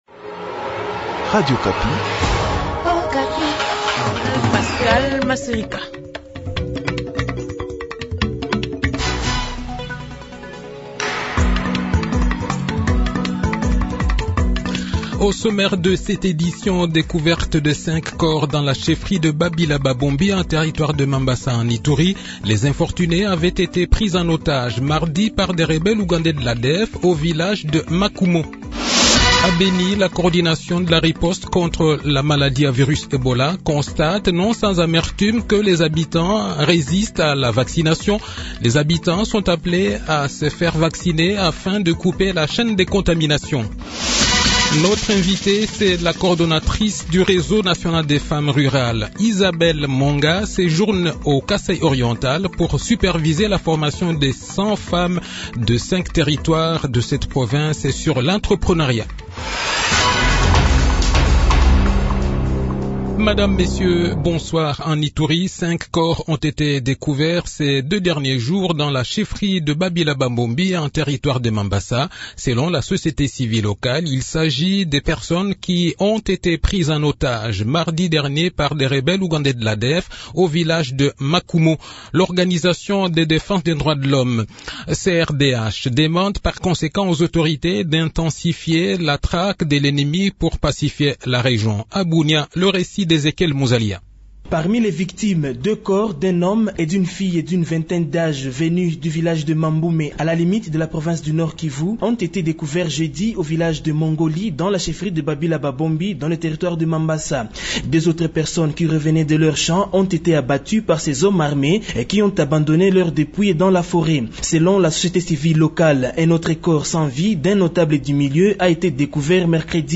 Le journal de 18 h, 22 Octobre 2021